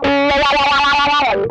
SITTING WAH3.wav